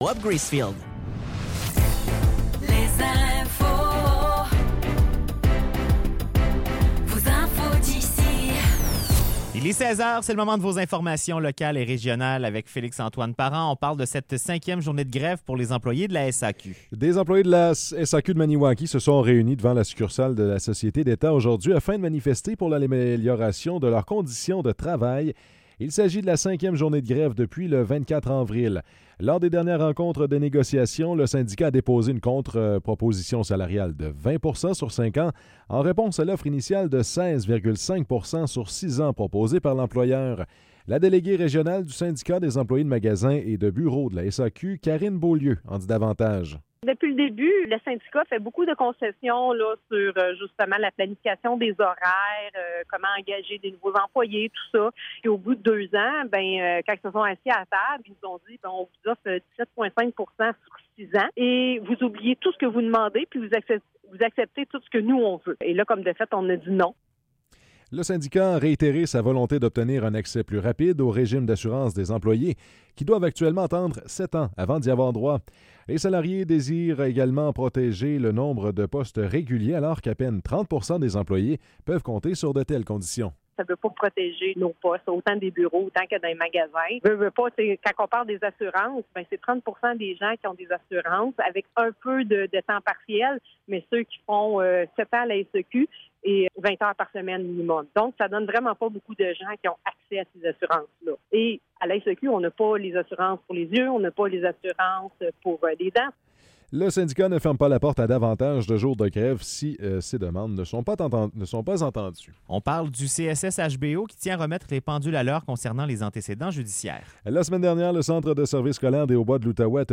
Nouvelles locales - 4 novembre 2024 - 16 h